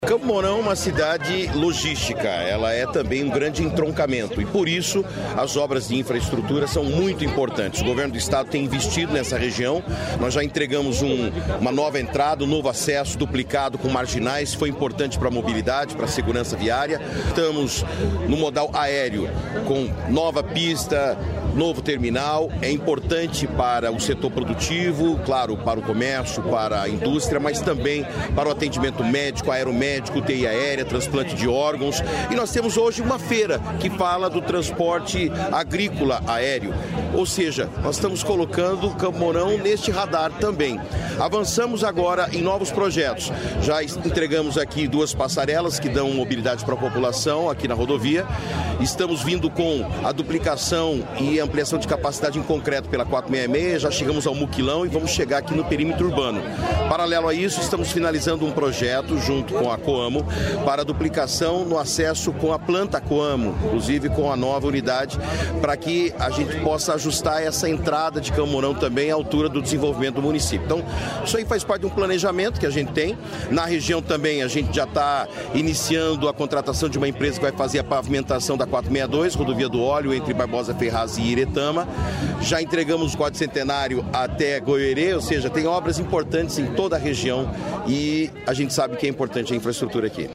Sonora do secretário de Infraestrutura e Logística, Sandro Alex, sobre a nova pista do aeroporto de Campo Mourão